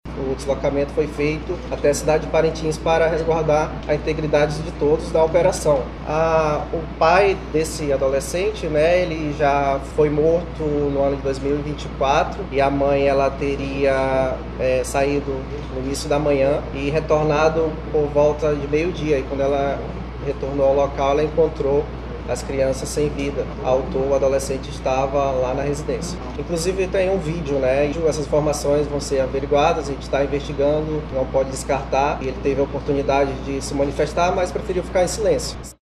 Em coletiva de imprensa realizada nesta segunda-feira (13)